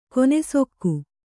♪ konesokku